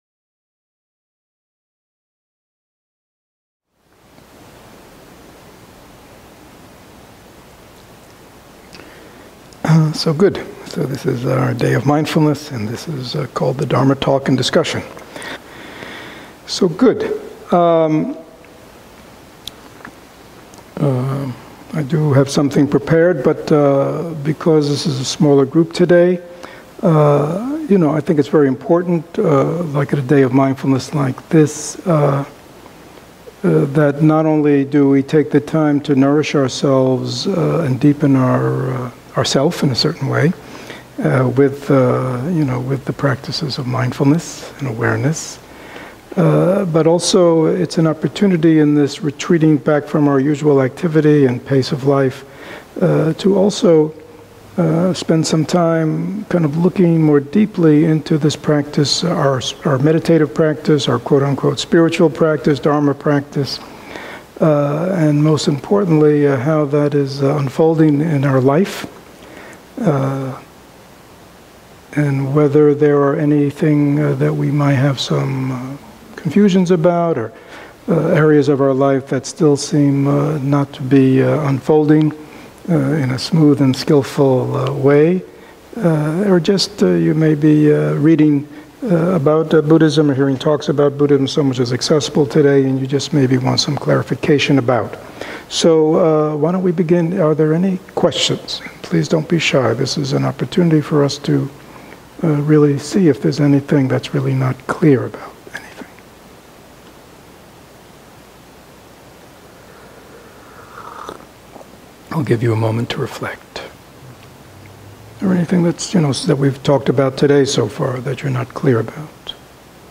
Tampa | Day of Mindfulness, October 17, 2015